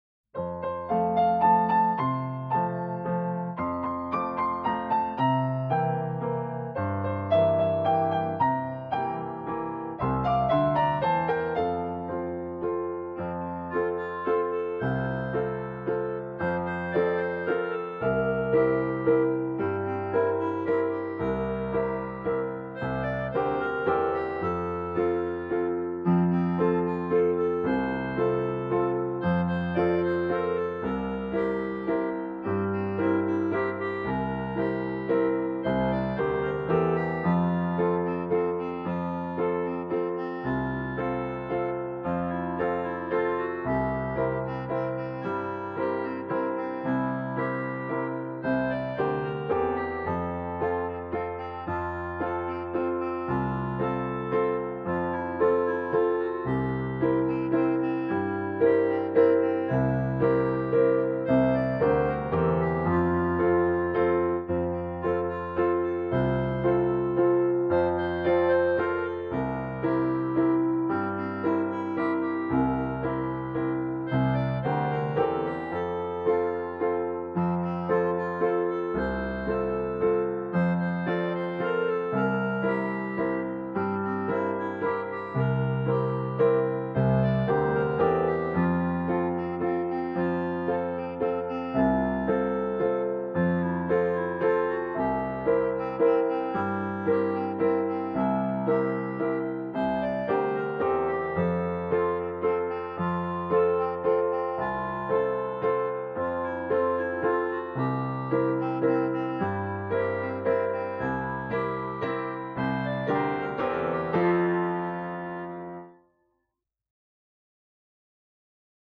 21-乘著馬車盪遊原野-伴奏.mp3